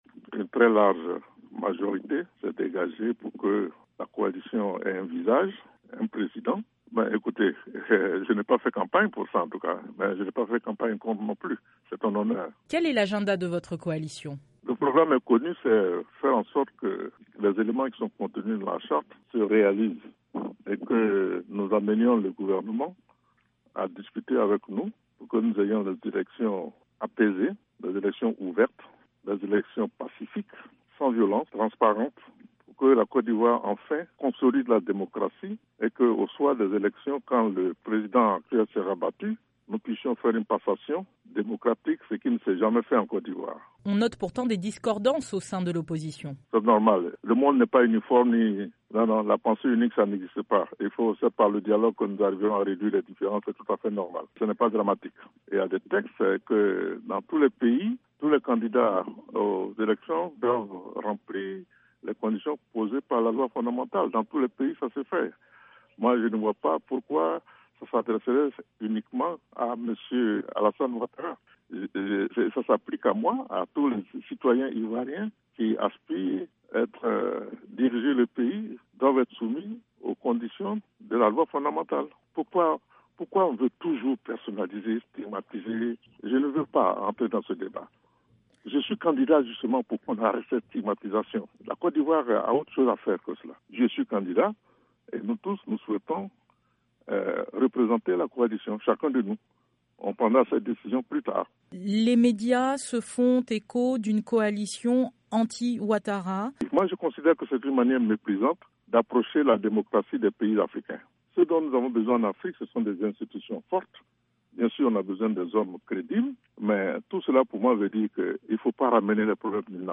Joint par VOA Afrique, M.Konan Banny explique que "le programme est que nous amenions le gouvernement à discuter avec nous pour que nous ayons des élections apaisées, ouvertes, pacifiques, sans violences et transparentes".